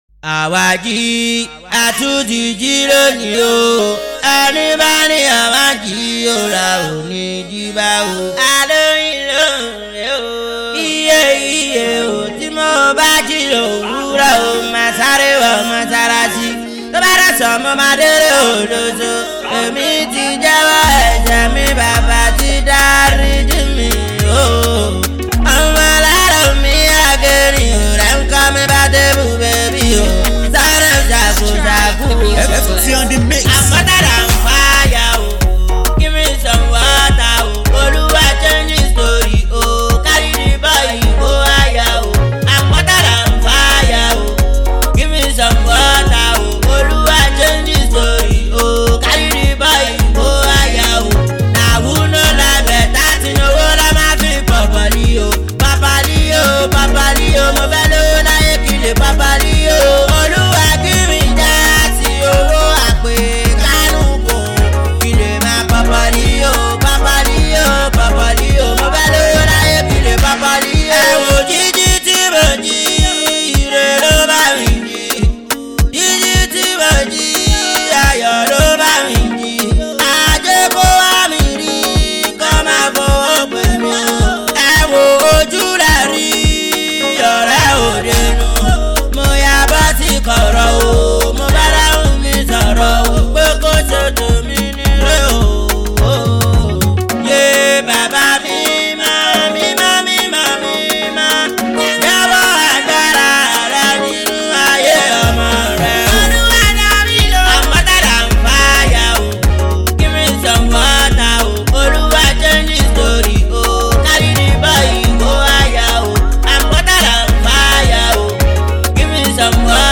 Talented Nigerian Indigenous Street Rapper/Singer